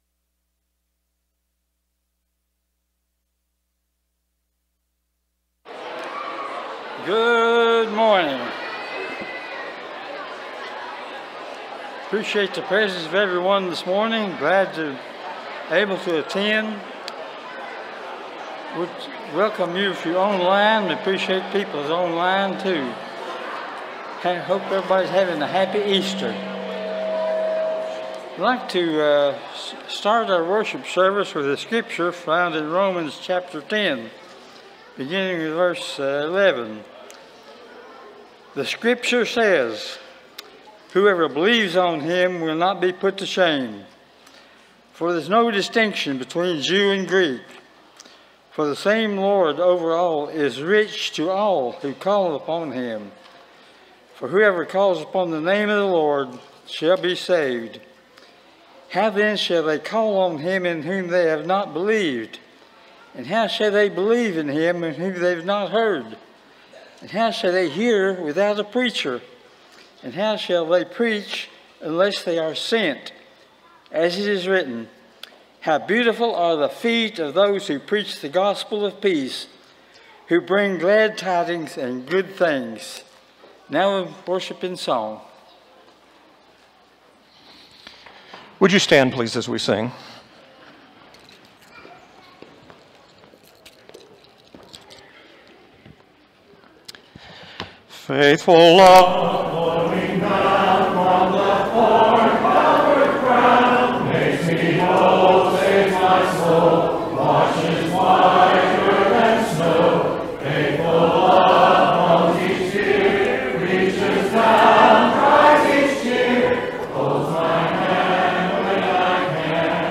Acts 2:32, English Standard Version Series: Sunday AM Service